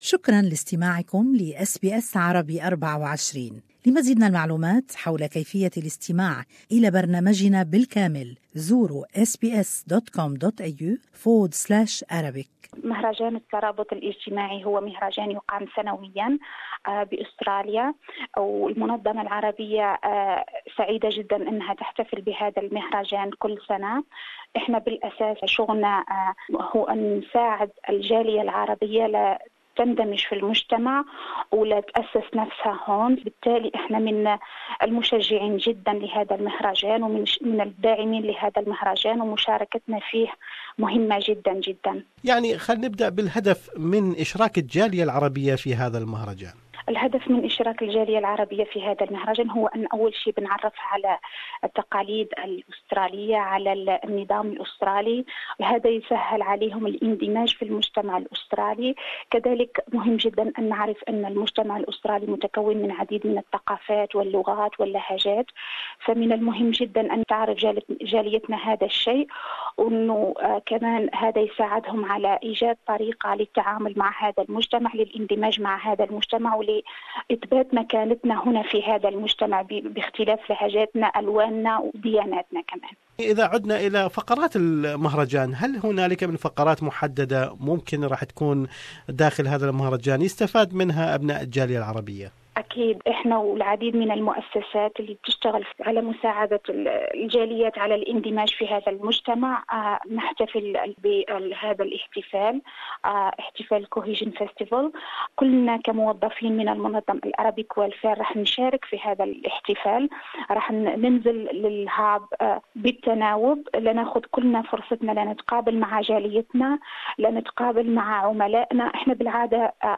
Arabic Welfare and other settlement services providers will take part in the social cohesion festival in northern Melbourne on 30 March. More is in this interview